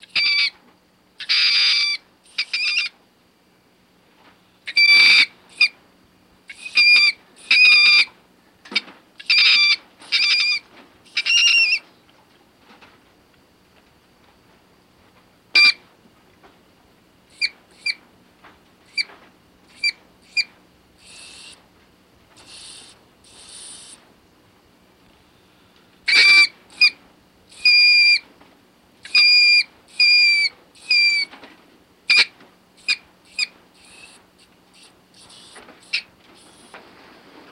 Mink Screams Flying Book Elements